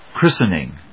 音節chrís・ten・ing 発音記号・読み方
/‐sn‐(米国英語)/